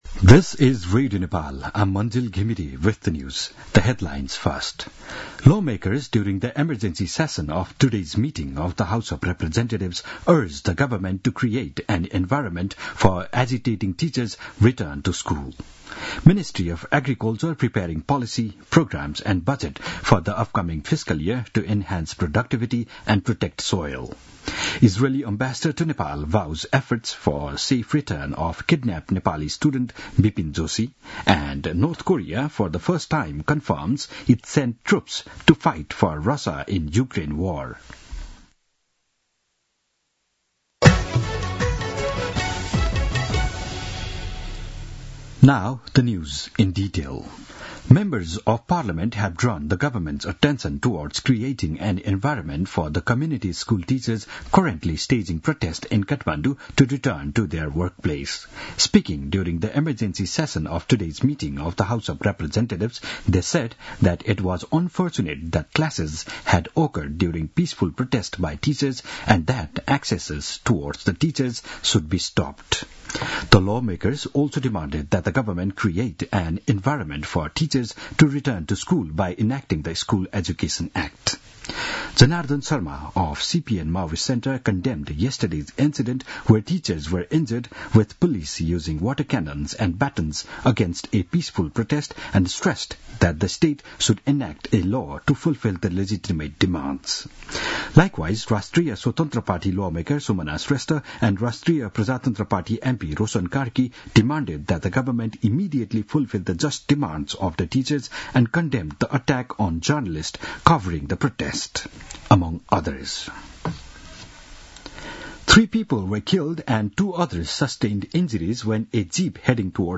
दिउँसो २ बजेको अङ्ग्रेजी समाचार : १५ वैशाख , २०८२
2-pm-news-1-12.mp3